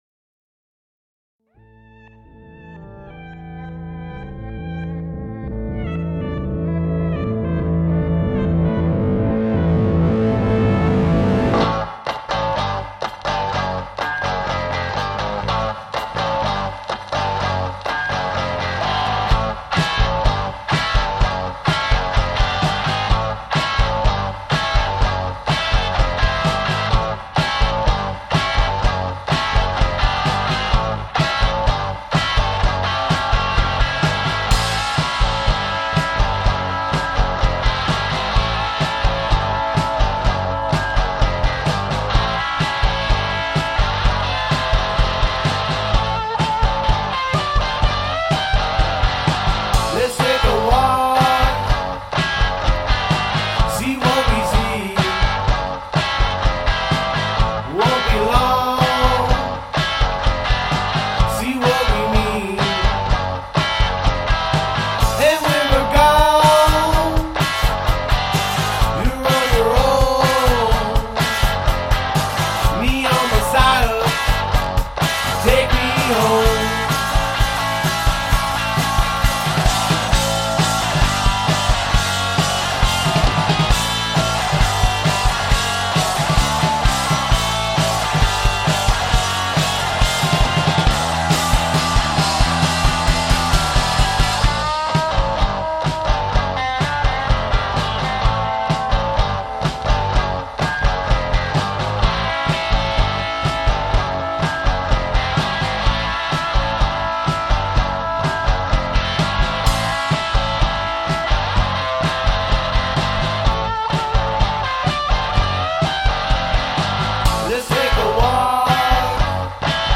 two piece garage/rock act
We like their lo-fi sound